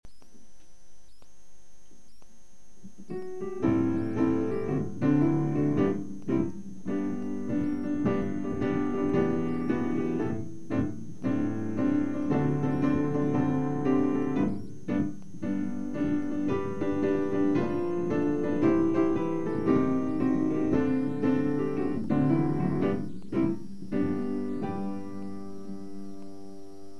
その日の演奏を再現→
振り返ると私はアレンジとかこる方なんで、手引きにあった楽譜のコードに満足できず、事務センターにコードは楽譜の通りじゃなくても大丈夫なのか事前に確認し、かなり独自のアレンジに変えたピアノ伴奏を弾きました。